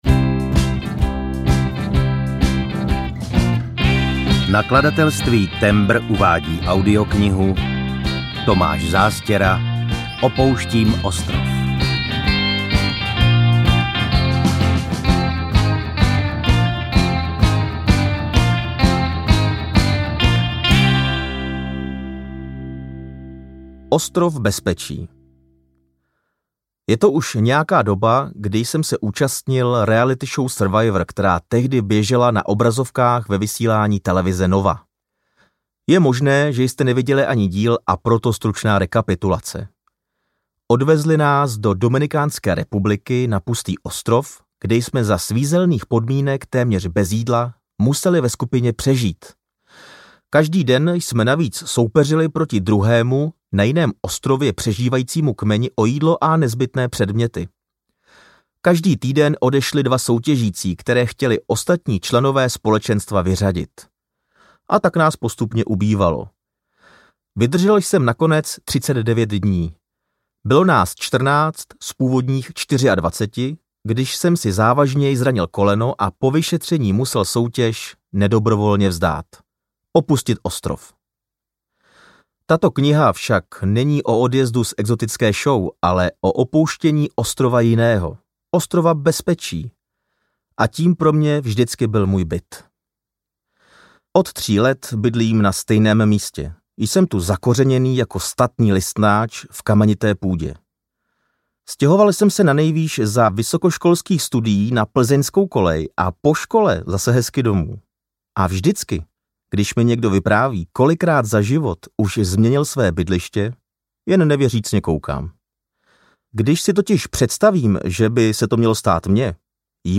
Opouštím ostrov audiokniha
Ukázka z knihy
opoustim-ostrov-audiokniha